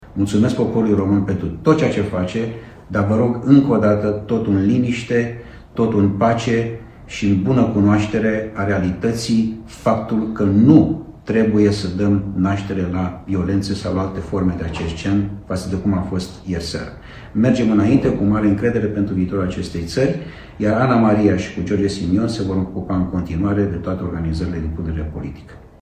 Călin Georgescu va contesta la CCR decizia Biroului Electoral Central, au declarat pentru presa internațională consilierii lui. Între timp, într-un videoclip publicat de George Simion, Călin Georgescu face apel la calm și le cere oamenilor „să urmeze instrucțiunile de la AUR și POT”.